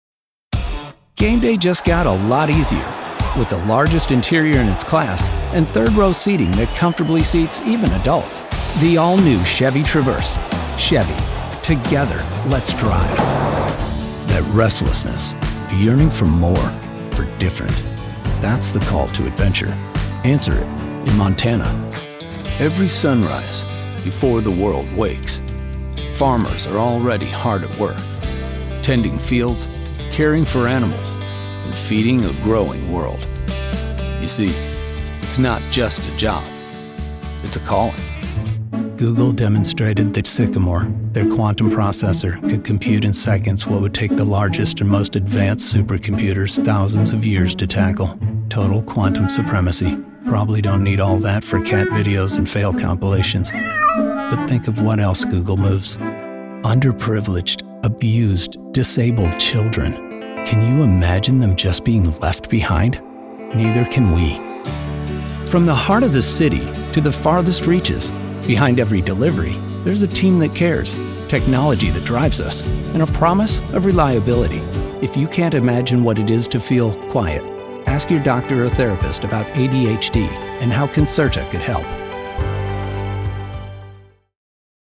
American Male Voice Talent - Friendly, Relatable, Confident, Authentic, Real
Commercial Demo 1.mp3
Middle Aged